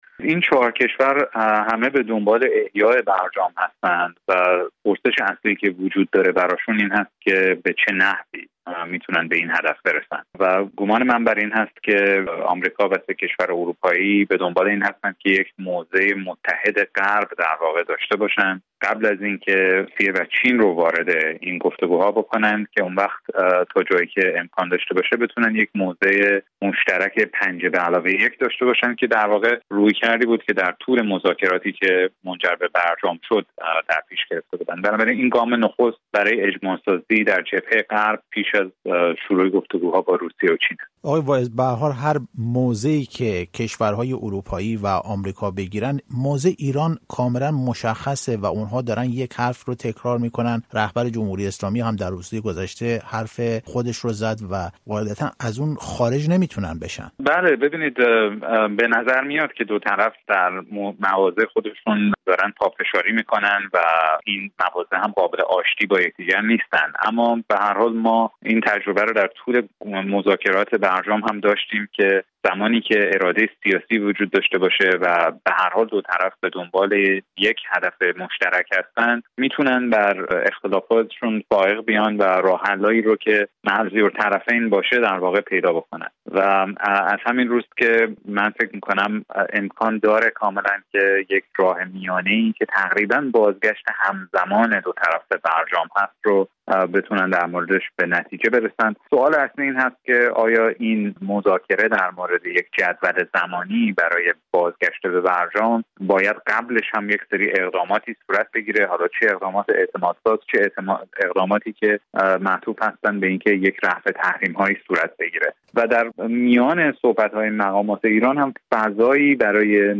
گفت‌وگو کرده‌ایم.